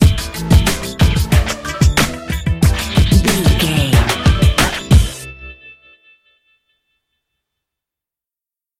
Aeolian/Minor
drum machine
synthesiser
percussion